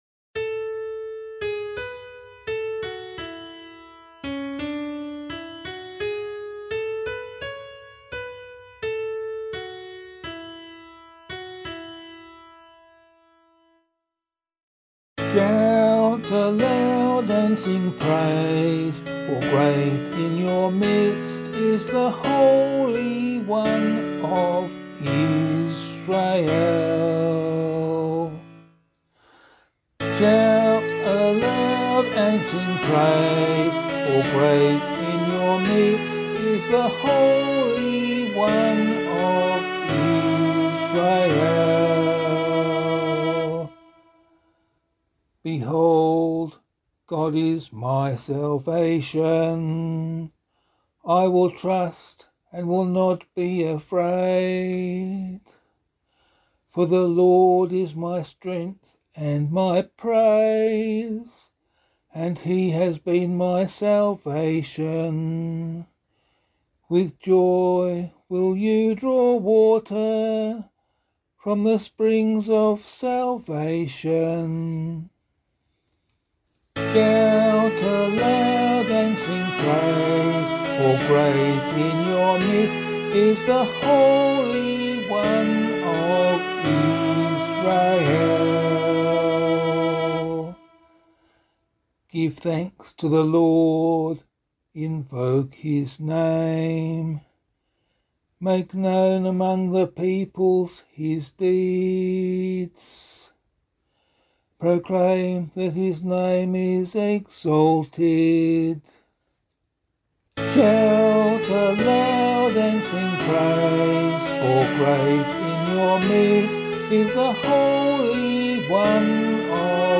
003 Advent 3 Psalm C [APC - LiturgyShare + Meinrad 8] - vocal.mp3